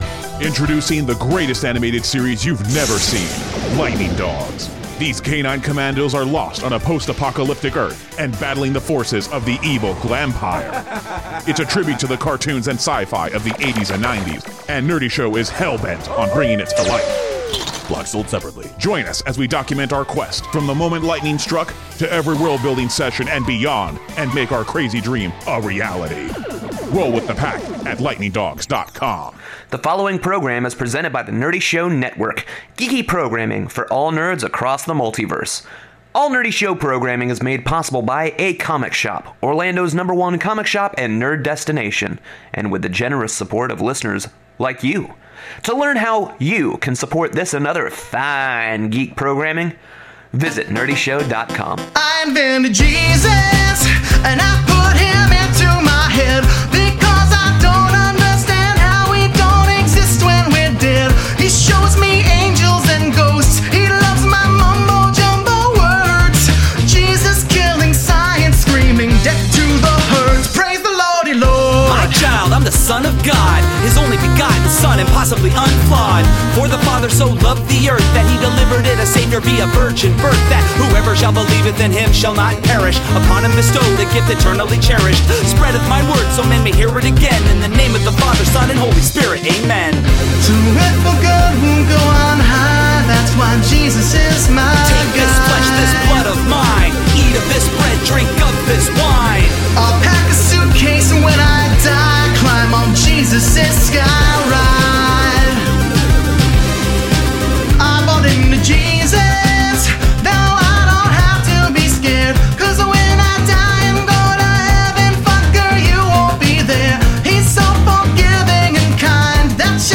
I They look back on the formative years of the earliest fests bearing this hallowed name, while we traipse through some of those early tunes from those bygone days - sandwiched by a couple of new nerdy jams for good measure!